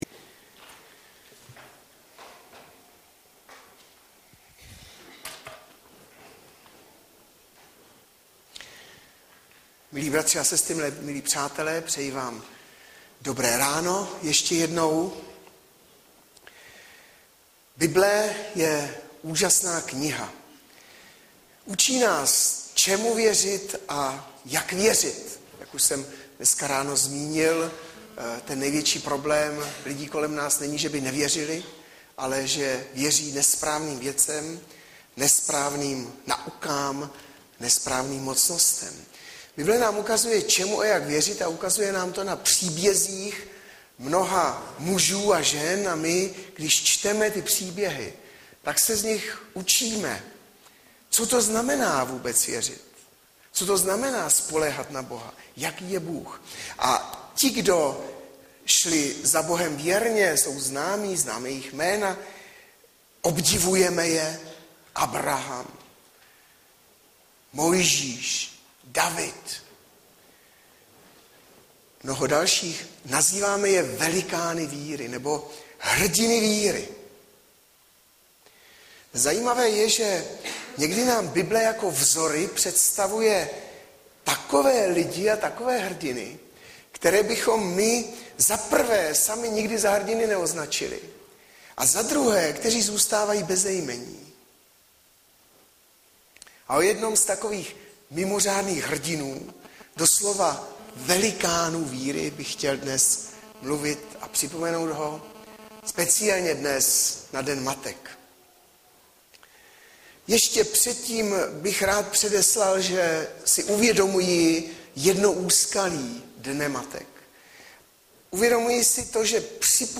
- Mat 15,21-28 Audiozáznam kázání si můžete také uložit do PC na tomto odkazu.